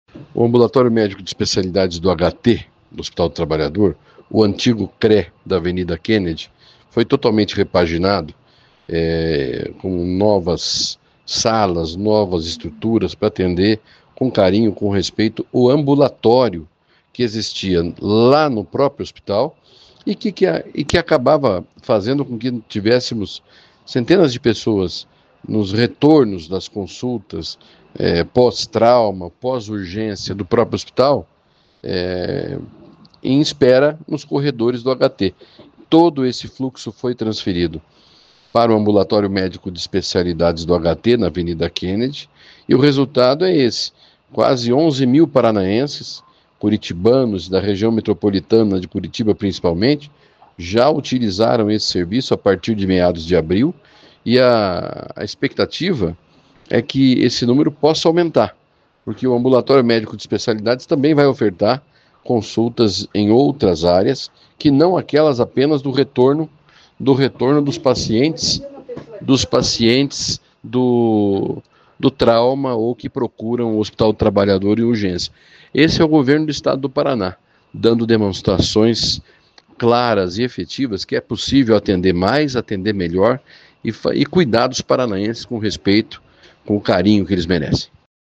Sonora do secretário da Saúde, Beto Preto, sobre os atendimentos do novo Ambulatório Médico Especializado do Hospital do Trabalhador